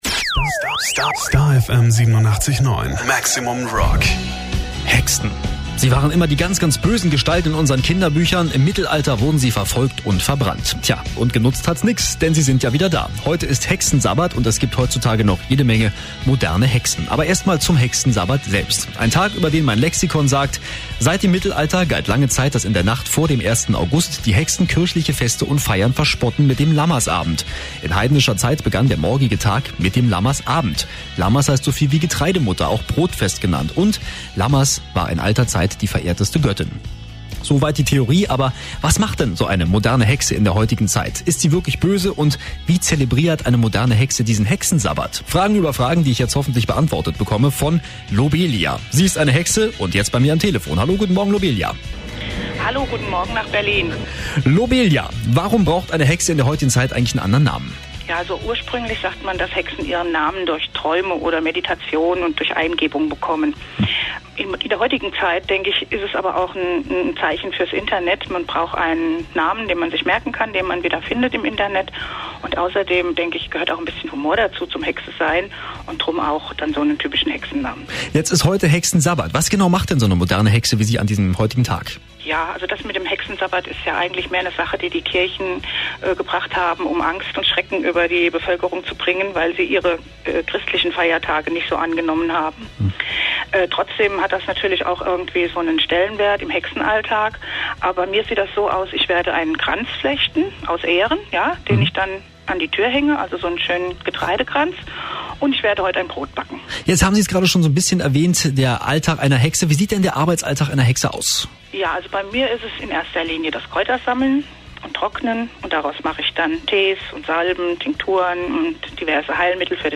Radio-Interview mit STAR FM Berlin - Breakfast Club - am 31. Juli 2006